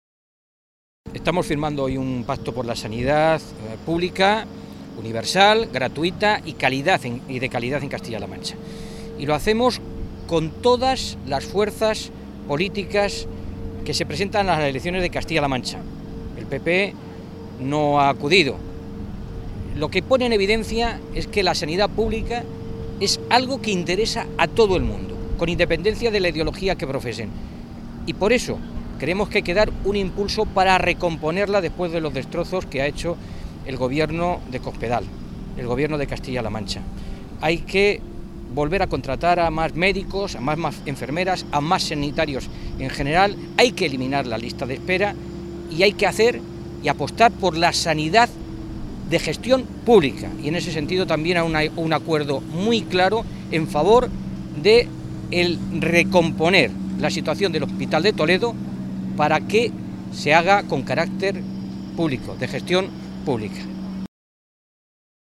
Mora realizaba estas declaraciones durante su intervención en el acto de firma del pacto en defensa de la sanidad pública que ha tenido lugar esta mañana en la biblioteca del Alcázar de Toledo.
Cortes de audio de la rueda de prensa